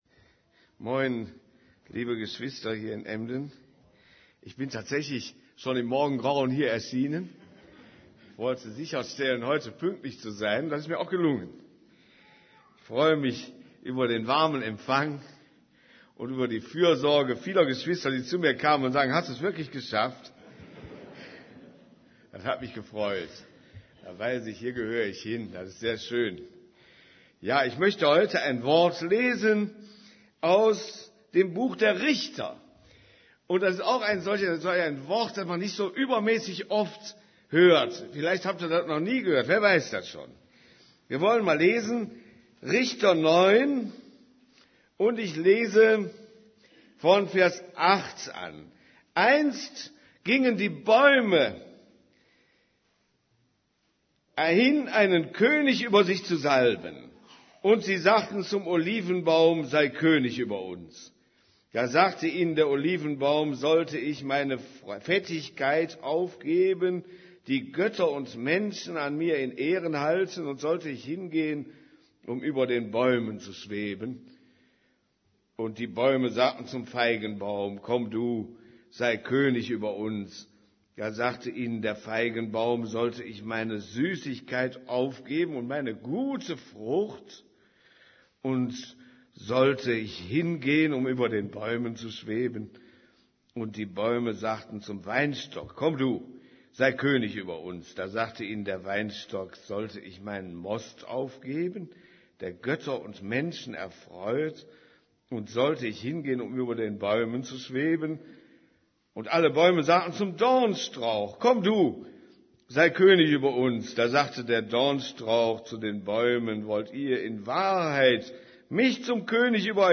> Übersicht Predigten In Christus fest verwurzelt bleiben Predigt vom 13.